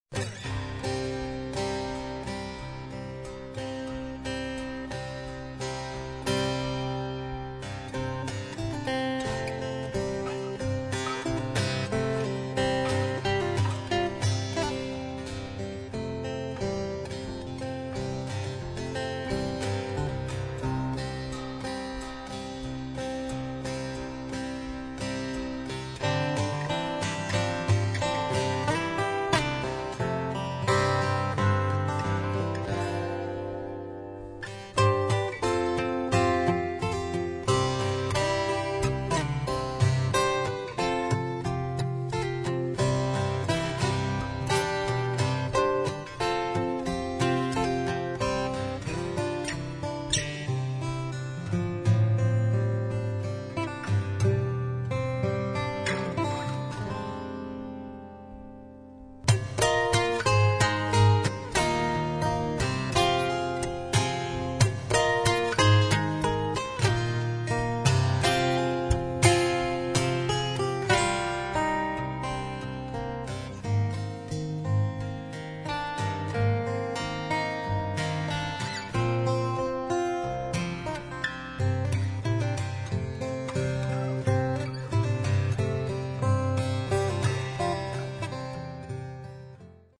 Performed on his Kevin Ryan guitar in a CGCGCD tuning